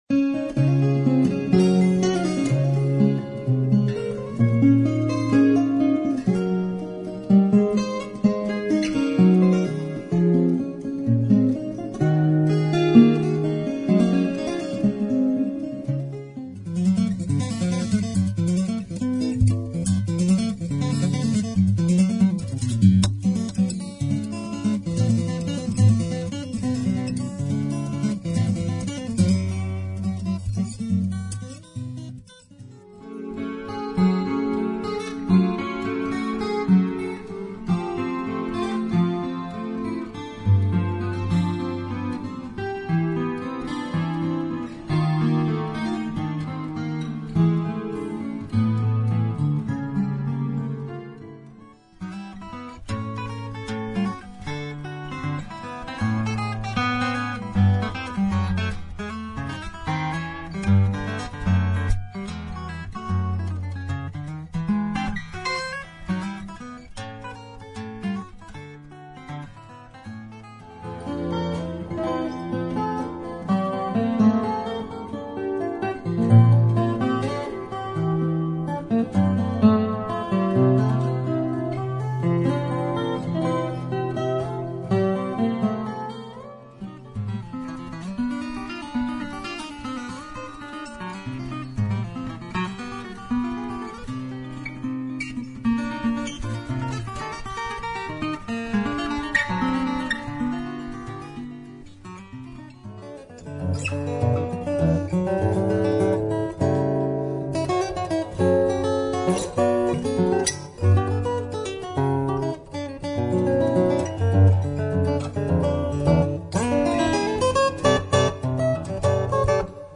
• Medley eingene Stücke instrumental
medley-my-own-instrumental.mp3